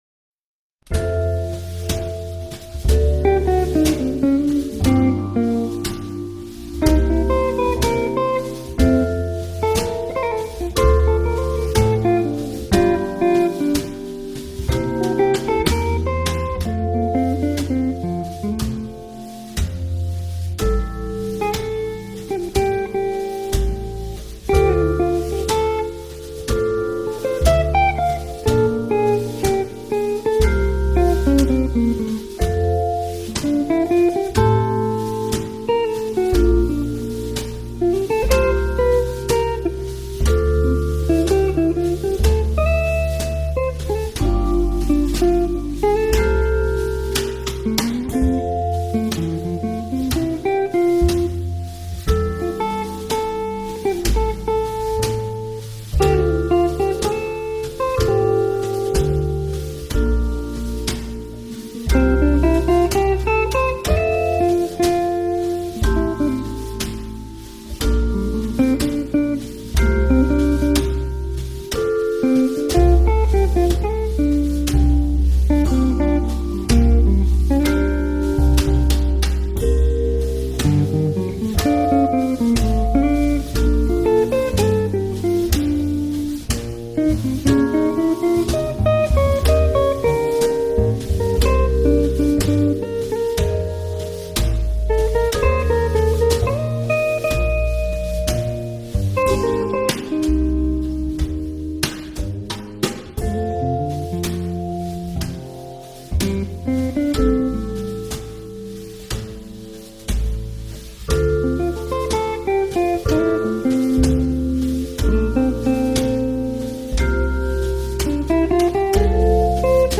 KGjefwU5fIl_Tarde-en-la-Ciudad-Música-Jazz-50k.mp3